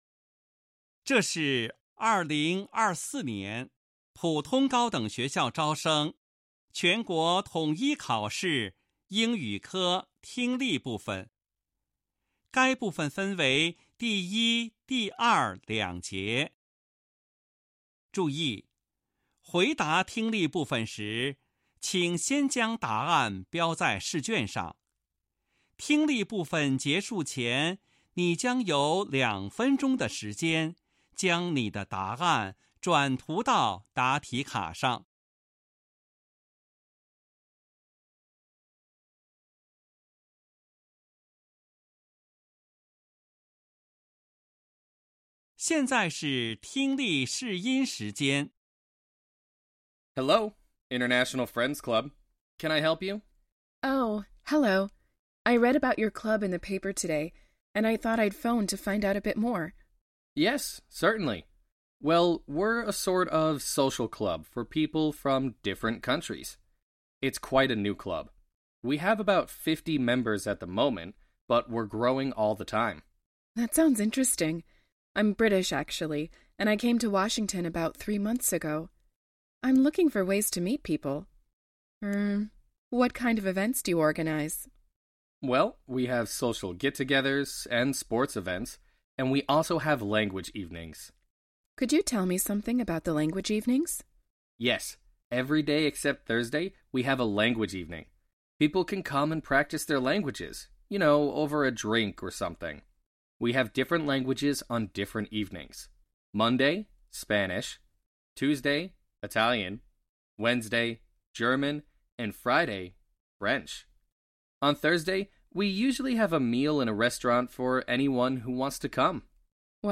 2024年高考英语试卷（新课标Ⅱ卷）听力音频.mp3